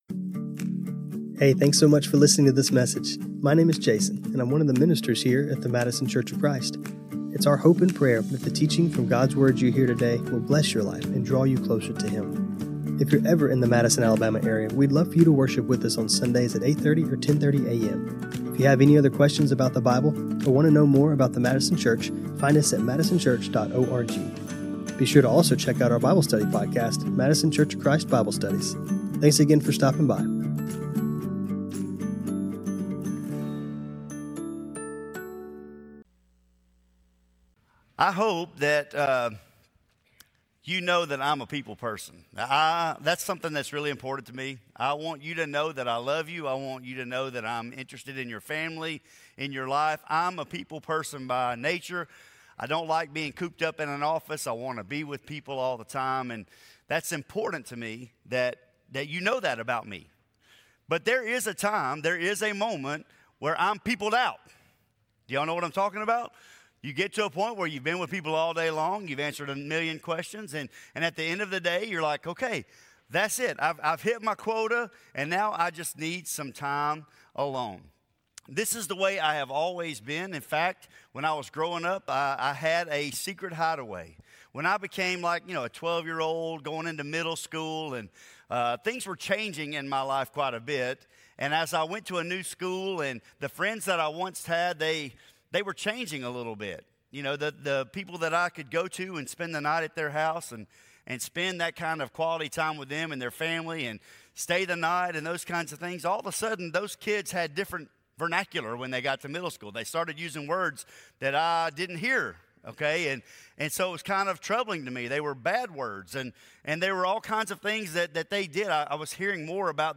Madison Church of Christ Sermons The Way of Jesus